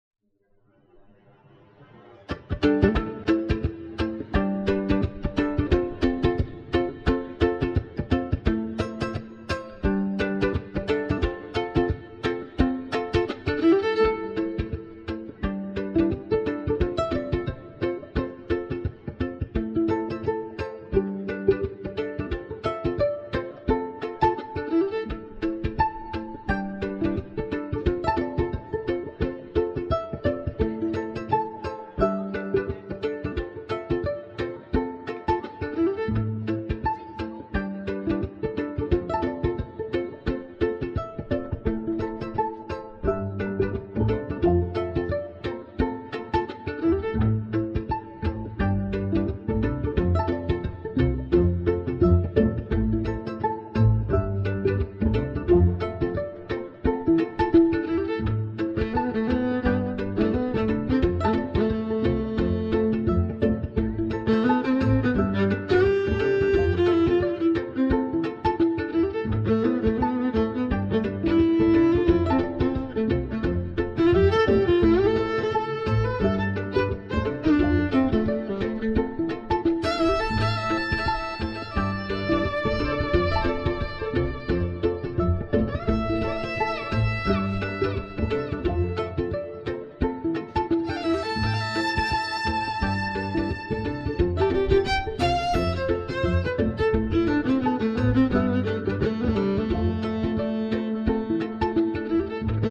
Jazz Crishendo